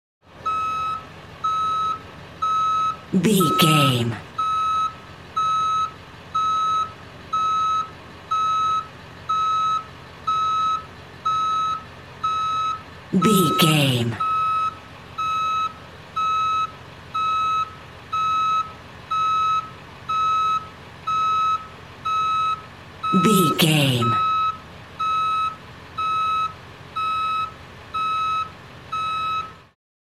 Ambulance Ext Reverse Beep
Sound Effects
urban
chaotic
anxious
emergency